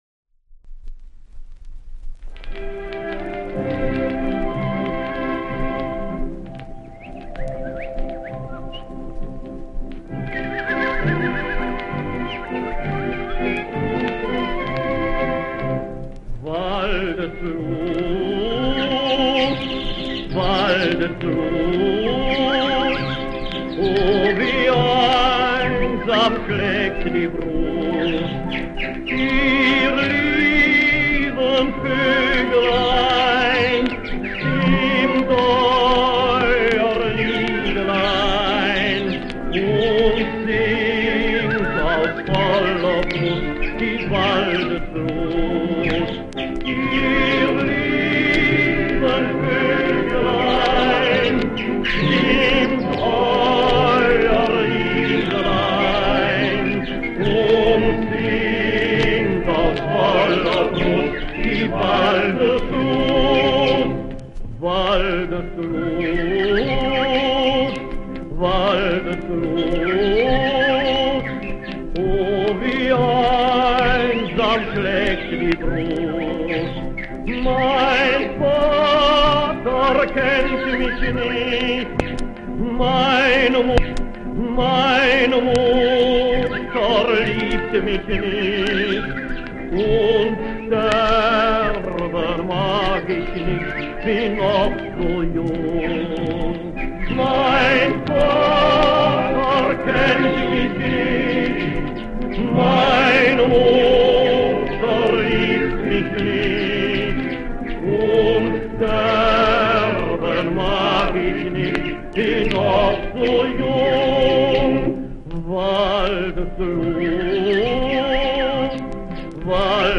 Waldeslust - Walzerlied
Volkstümliches Lied, entstanden um 1850, der Verfasser des Liedes ist unbekannt.
Auf Youtube sind 4 (alte) Aufnahmen vorhanden (Schelllack)
August 1966 in Zürich) war ein deutscher Sänger und Vortragskünstler.